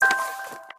s_common_button_2.wav